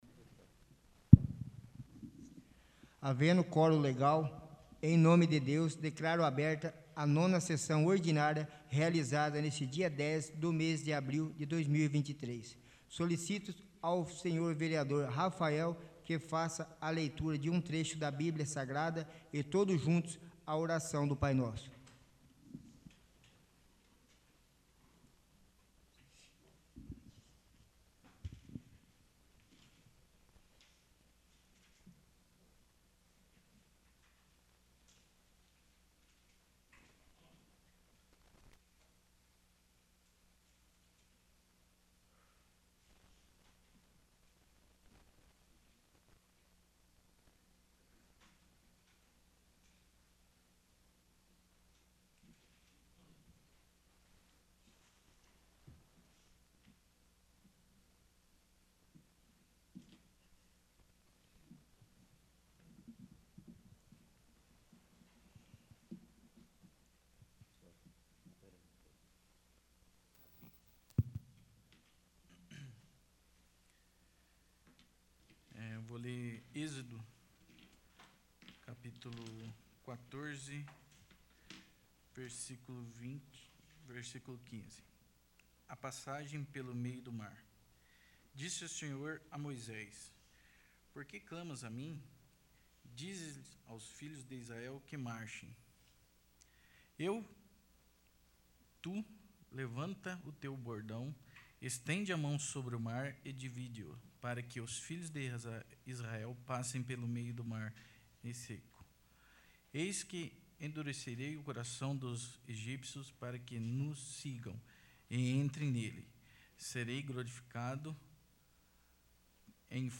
8º. Sessão Ordinária